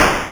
balloon_pop2.wav